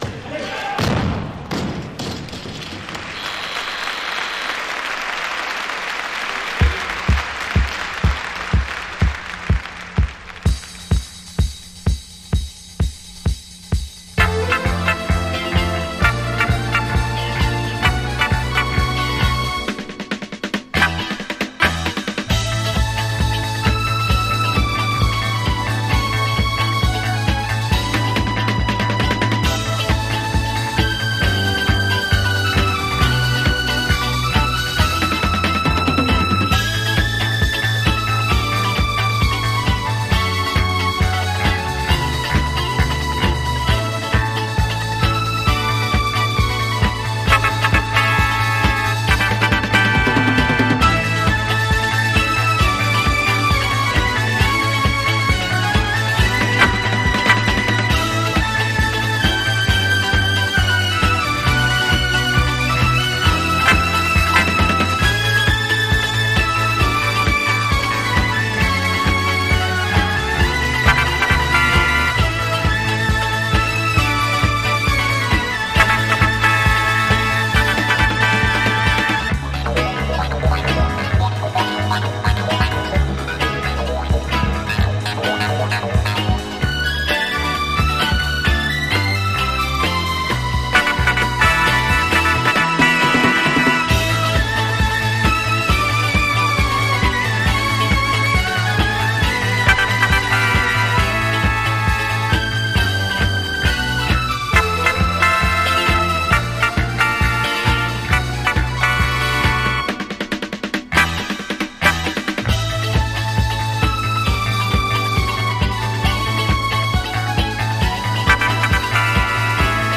ソリッドに弾ける洒脱でキラーなフレンチ・ジャズ・ファンク！
BASS
DRUMS
GUITAR
KEYBOARDS